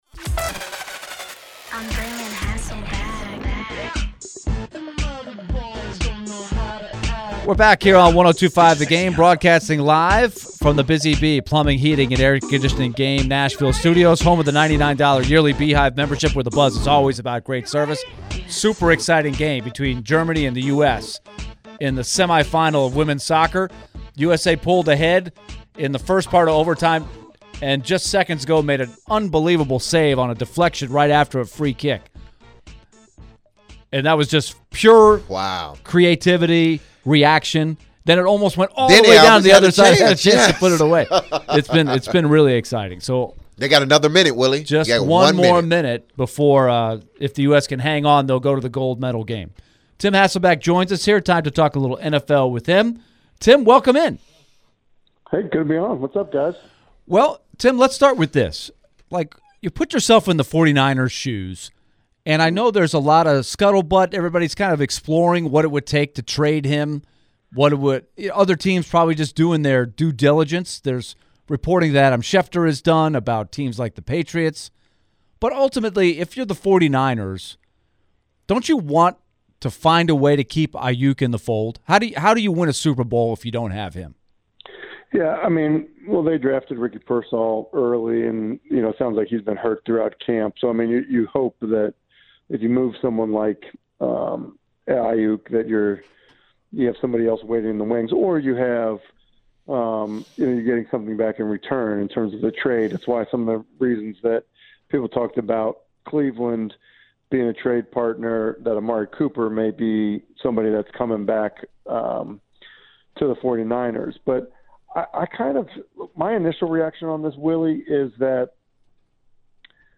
ESPN NFL Analyst Tim Hasselbeck joined the show. Tim was asked about Brandon Aiyuk's trade options that are currently happening around the league.